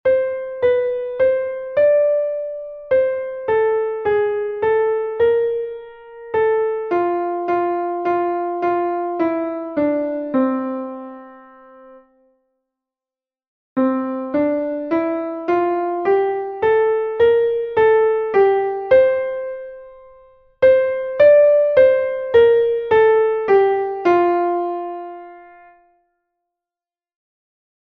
Poco_Lento.mp3